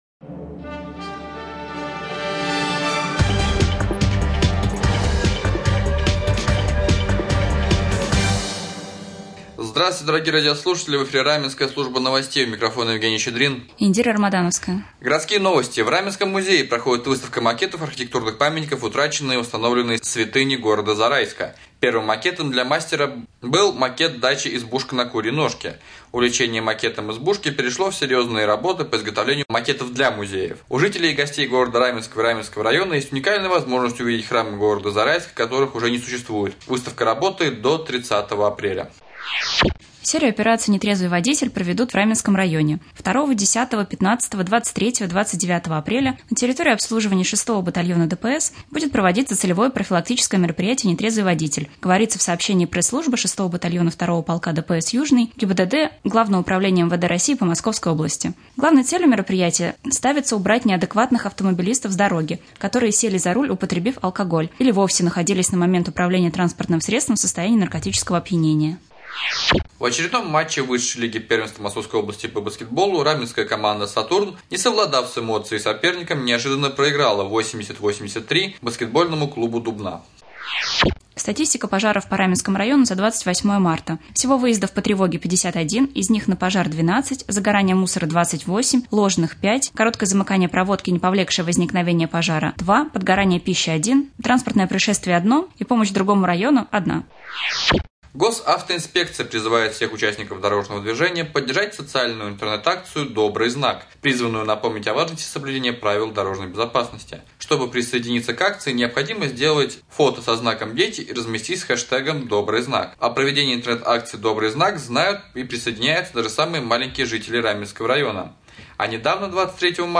1. В прямом эфире специалисты ветеринарной клиники "Ветмастер" г.Бронницы 2. Новости